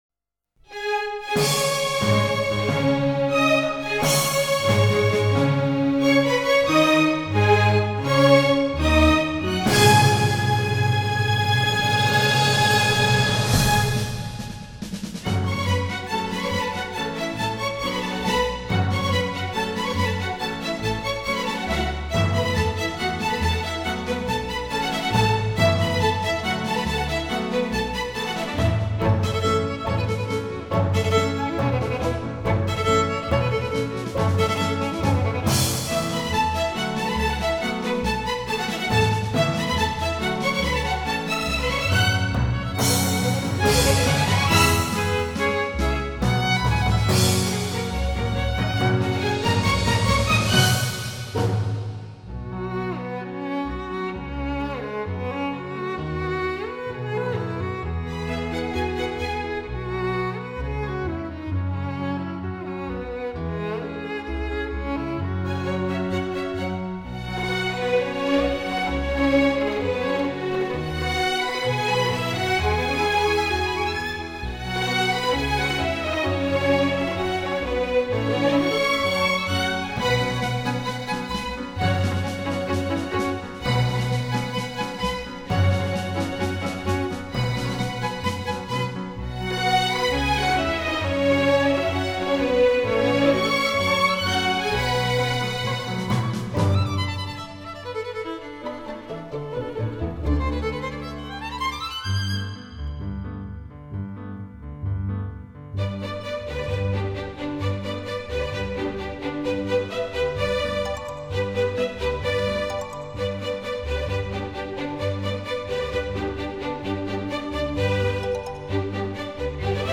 小提琴弦乐群的那种甜美、人性而辉煌的音色